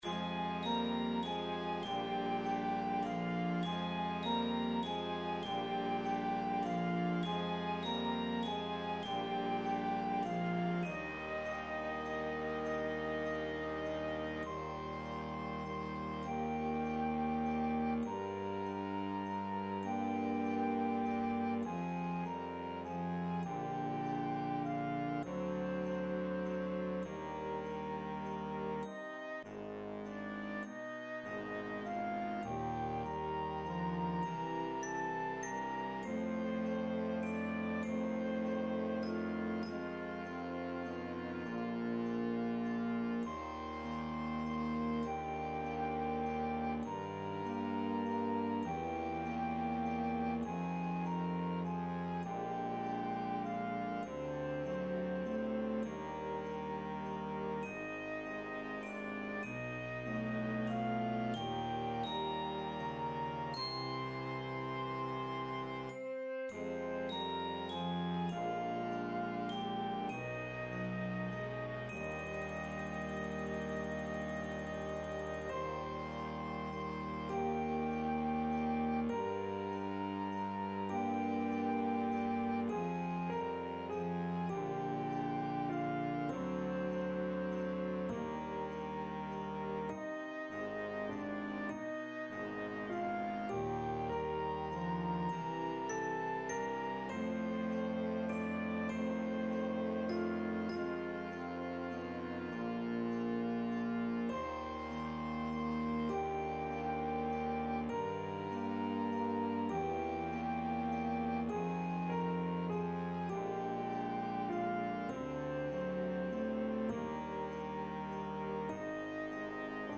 with optional vocal part